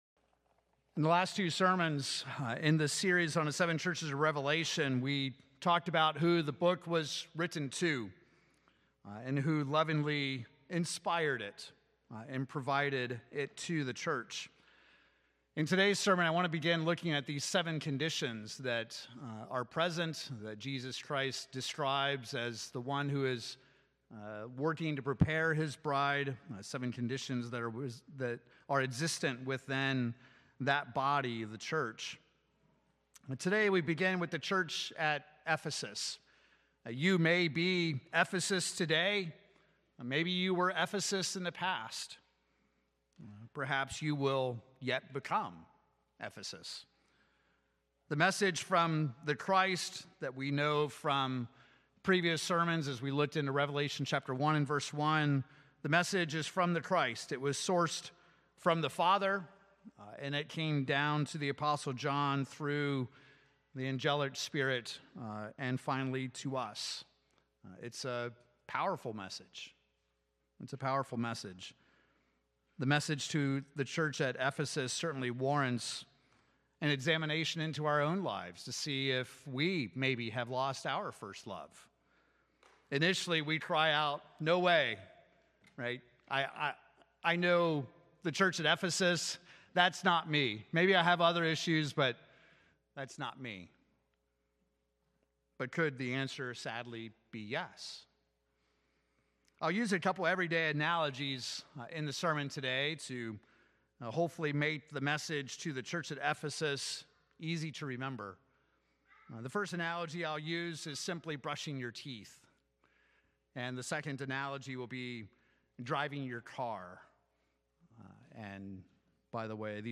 Given in Oklahoma City, OK Tulsa, OK